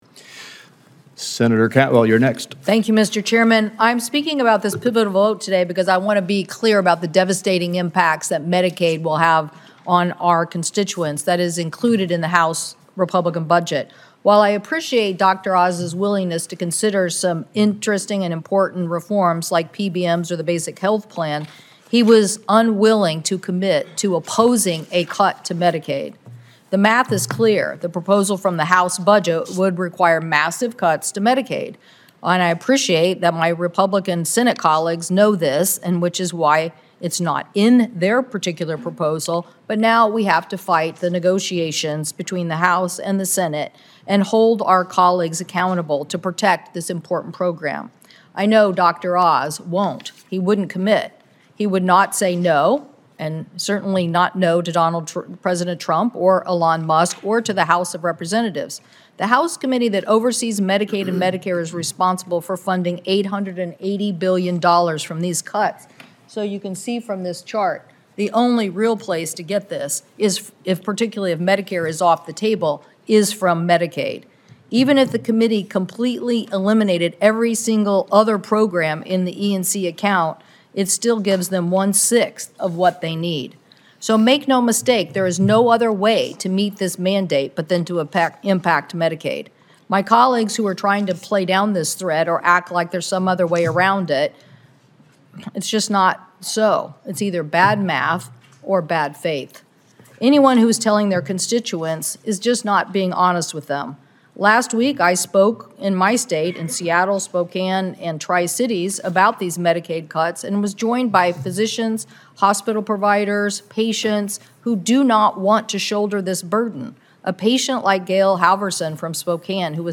During a markup today of the Senate Finance Committee, Sen. Cantwell cited Dr. Oz’s refusal to stick up for Medicaid during his hearing earlier this month, especially in the face of a draconian GOP budget bill that would necessitate massive cuts.